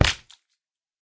land_hit4.ogg